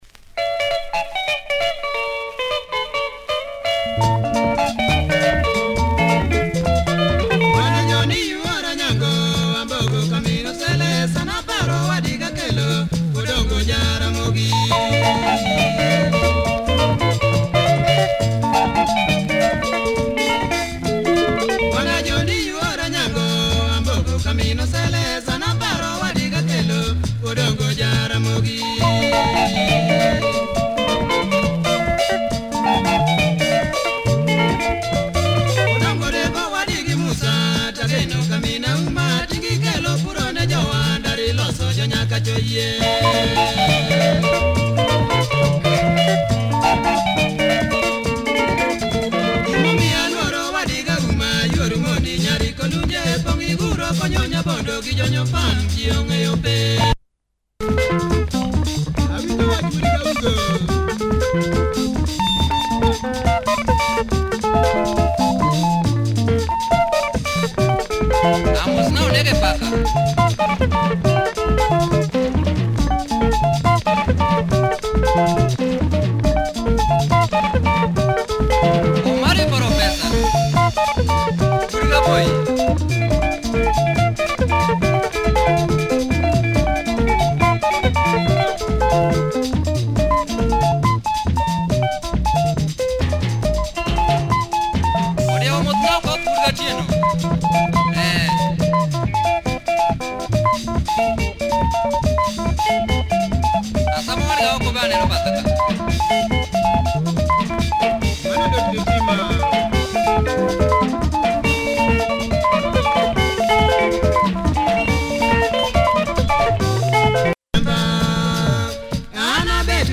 Tight LUO benga